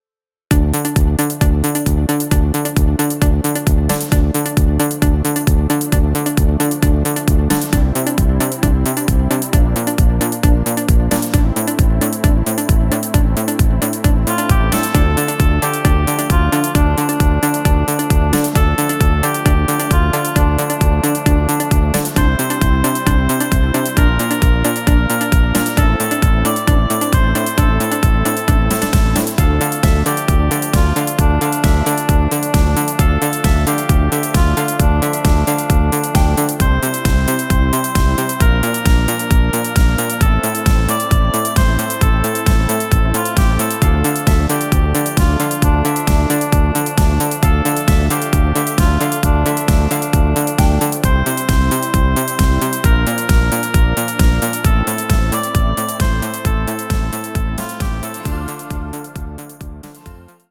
Synth Pop Hit der 80er